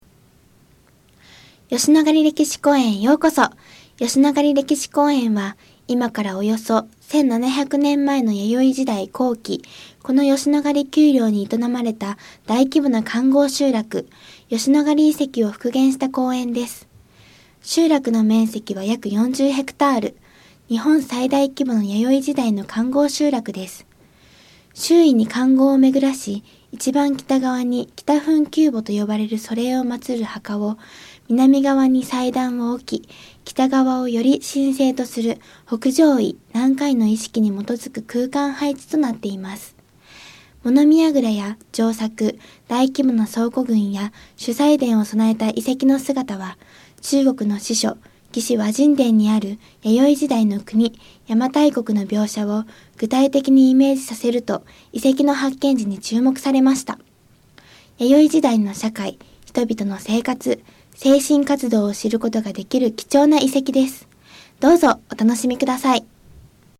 音声ガイド 次のページ ケータイガイドトップへ (C)YOSHINOGARI HISTORICAL PARK